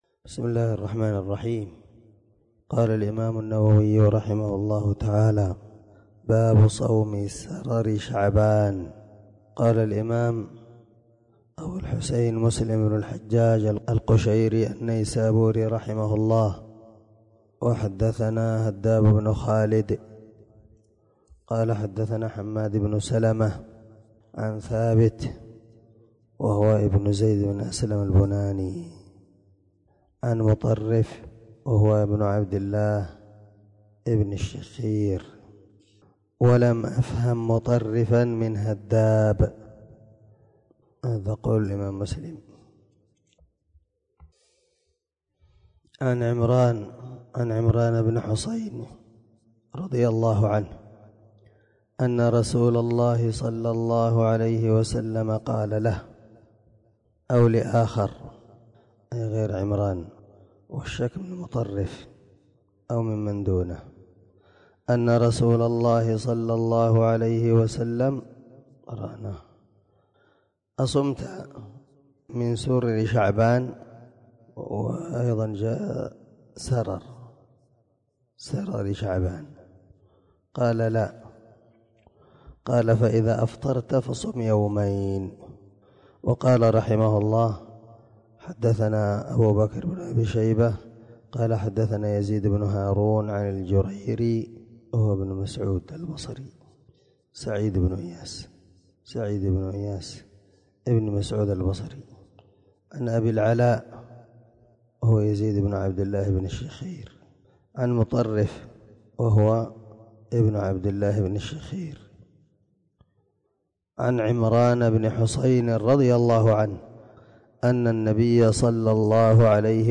709الدرس_43من_شرح_كتاب_االصيام_حديث_رقم1161_1163_من_صحيح_مسلم